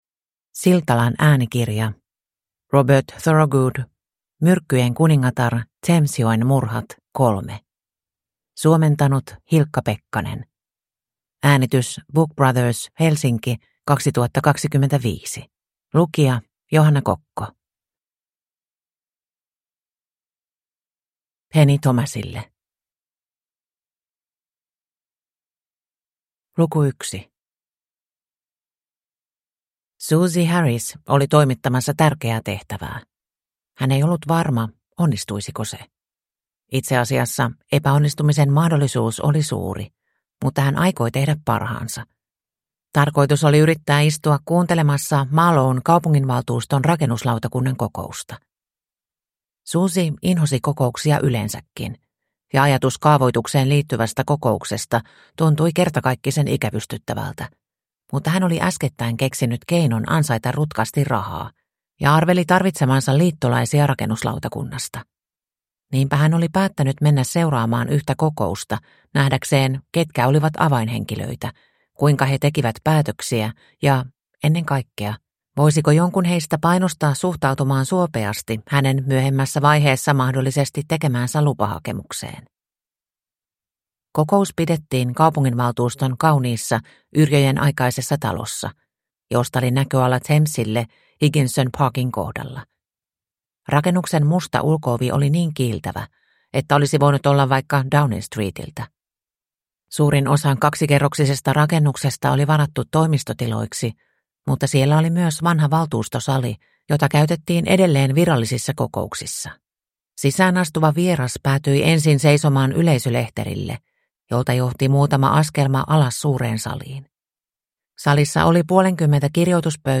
Myrkkyjen kuningatar (ljudbok) av Robert Thorogood